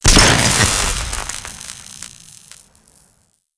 fx_explosion_grenade_pulse_high_01.wav